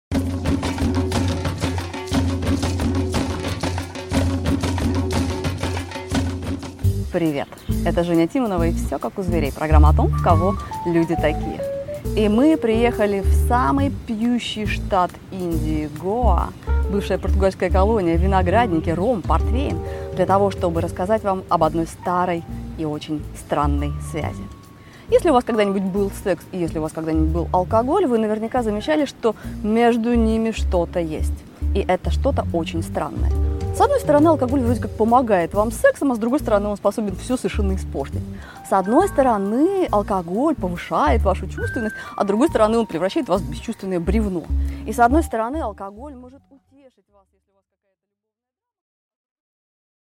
Аудиокнига Странные связи: секс и алкоголь | Библиотека аудиокниг